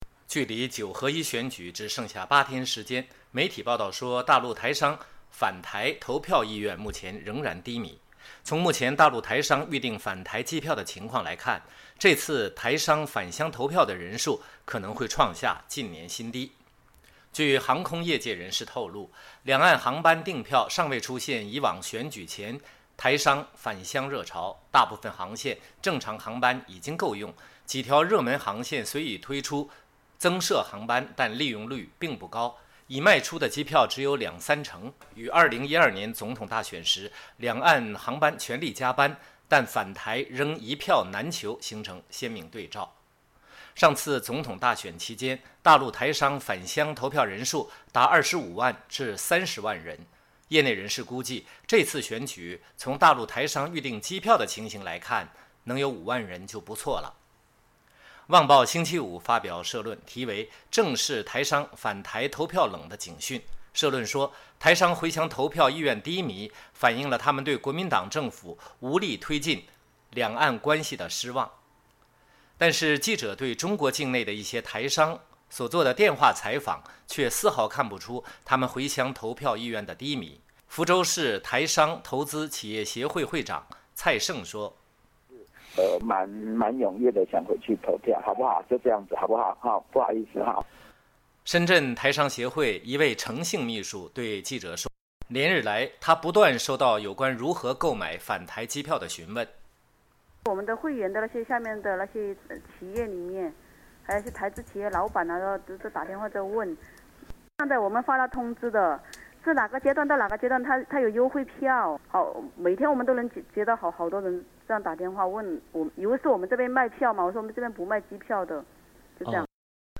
但是记者对中国境内一些台商所做的电话采访却丝毫看不出他们回乡投票意愿的“低迷”。